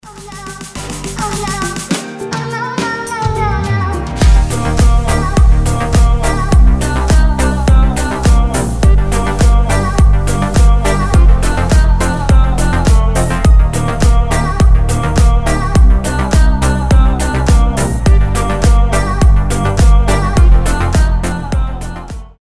deep house
веселые
house
ремиксы
Свежий ремикс мемной песенки